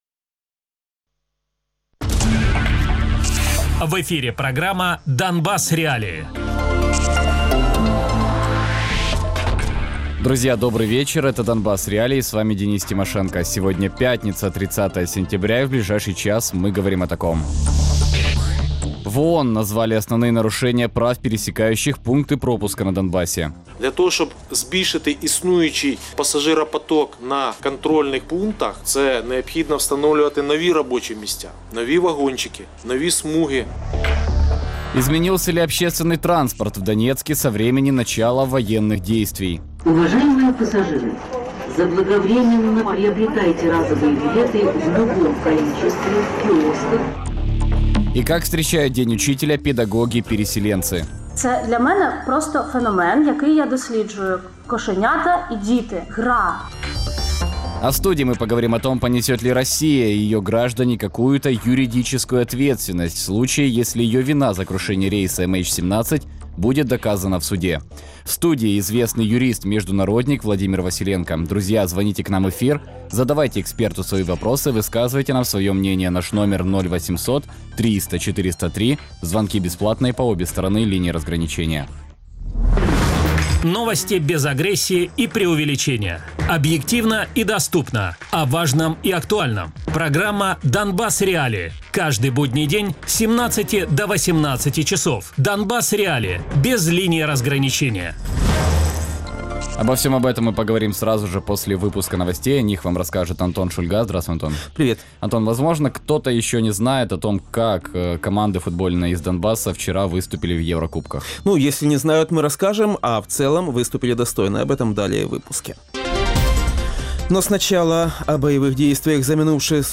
Гость: Владимир Василенко, чрезвычайный и полномочный посол Украины, представитель Украины в Совете ООН (2006-10 гг.), судья Международного Криминального Трибунала по бывшей Югославии (2001-2005), юрист-международник Радіопрограма «Донбас.Реалії» - у будні з 17:00 до 18:00.